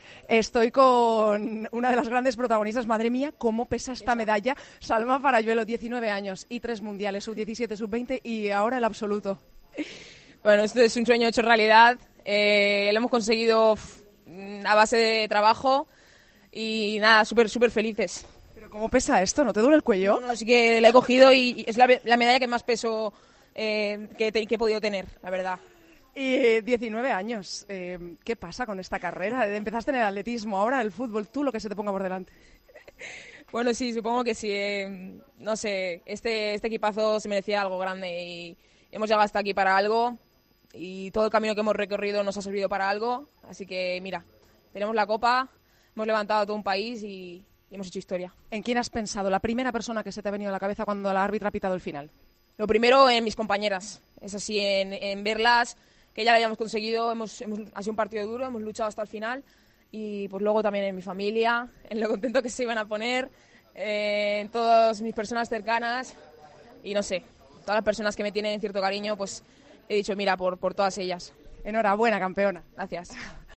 Salma Paralluelo habló en los micrófonos de Tiempo de Juego tras ganar el Mundial de Australia y Nueva Zelanda.